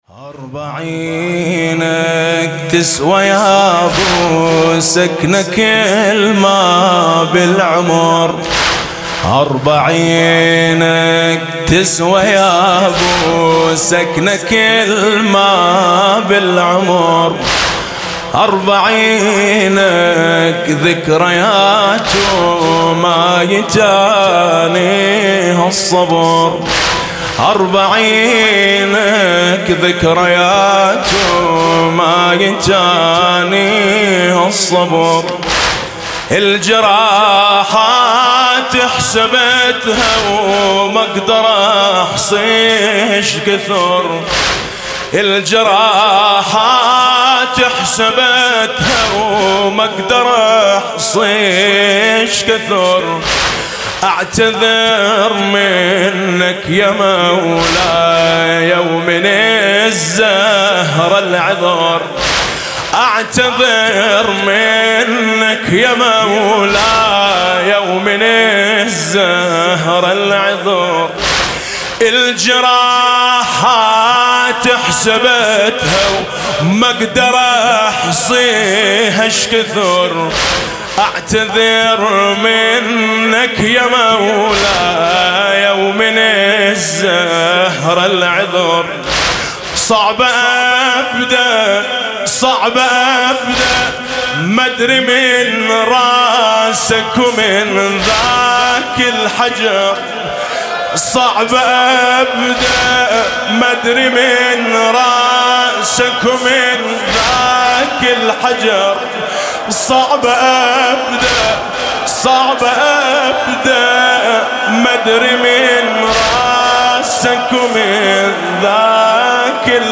مداحی عربی